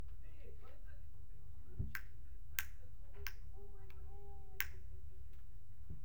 如果我增加 PGA、则连接的单端麦克风仅在设计中的这两个通道中、所记录的 o/p 具有更多的环境噪声